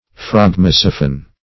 Phragmosiphon \Phrag`mo*si"phon\, n.